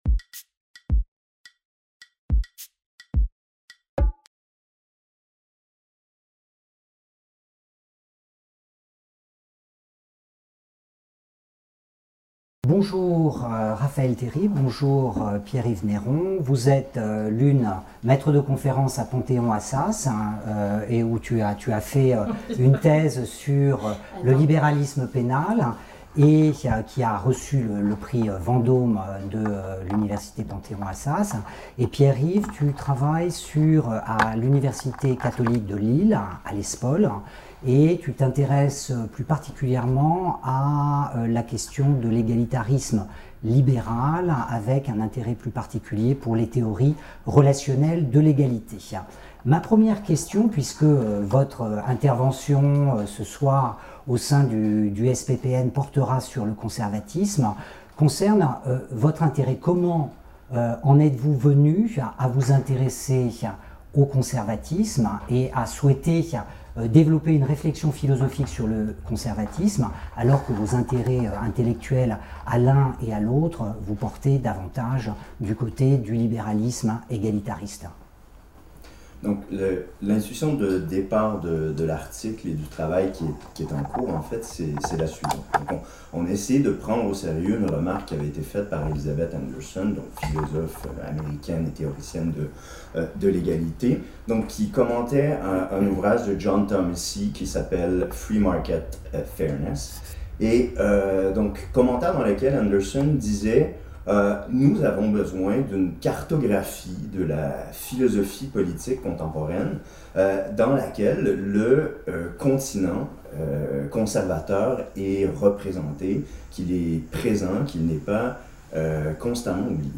Où est le conservatisme (dans la théorie politique contemporaine) ? Entretien